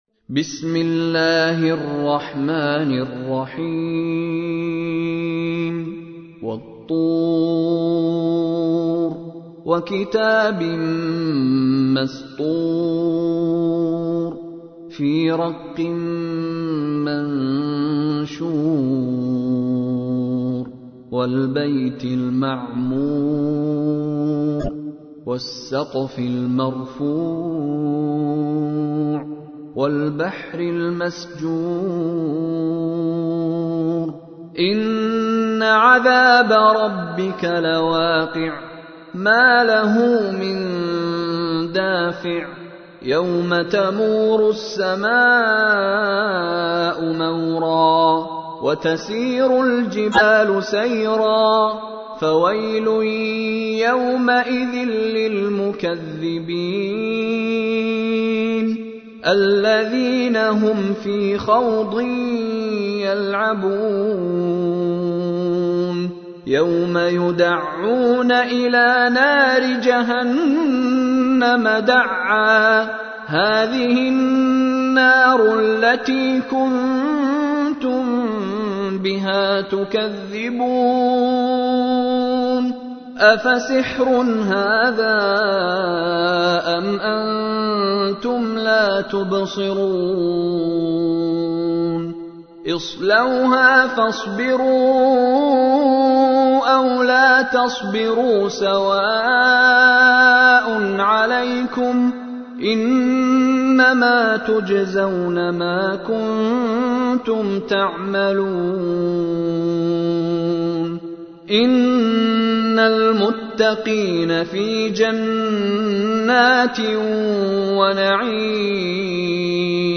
تحميل : 52. سورة الطور / القارئ مشاري راشد العفاسي / القرآن الكريم / موقع يا حسين